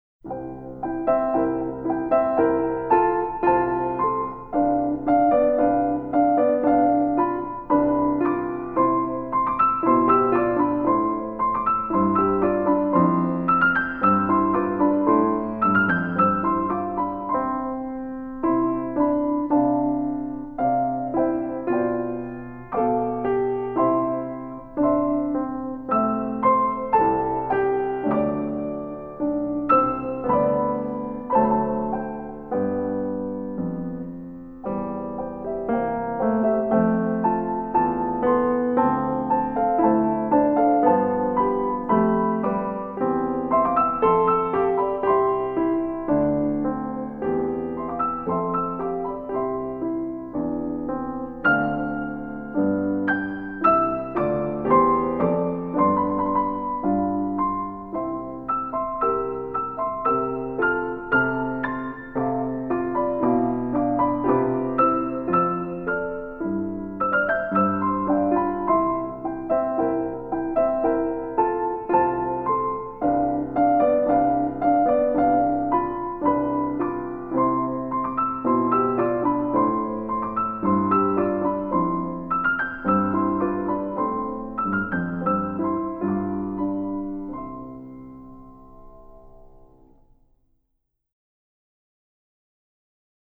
神圣